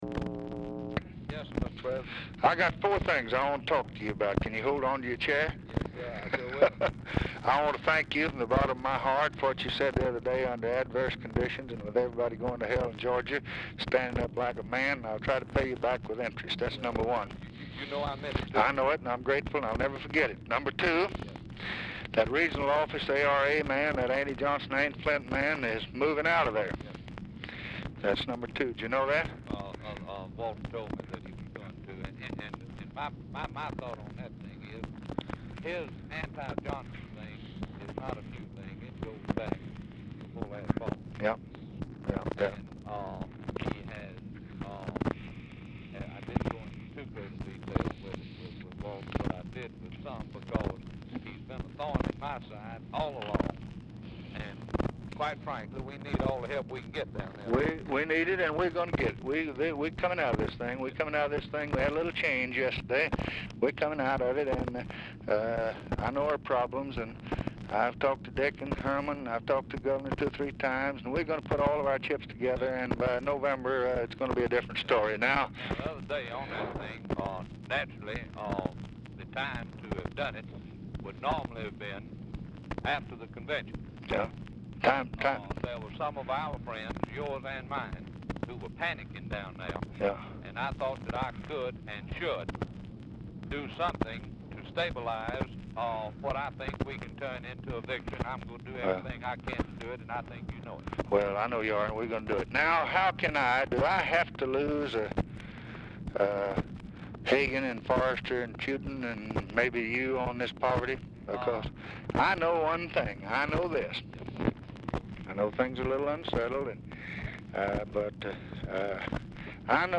Telephone conversation
Format Dictation belt